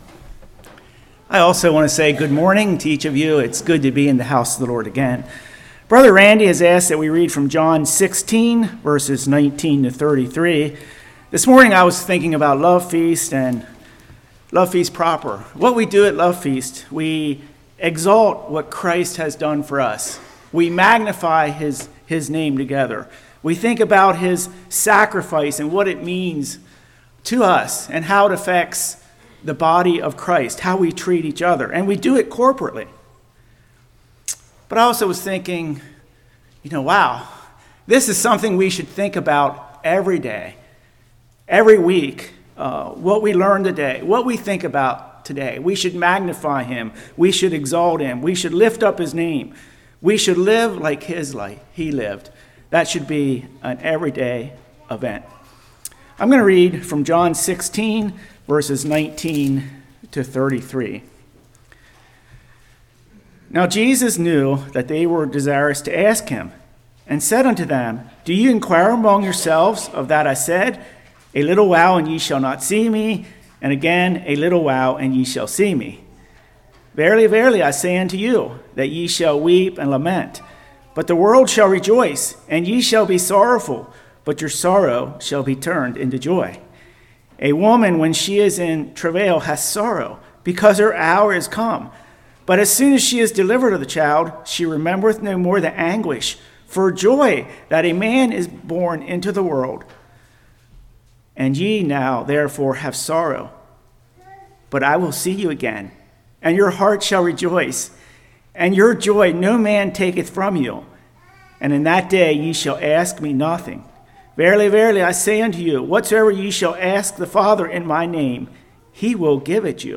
Passage: John 16:19-33 Service Type: Morning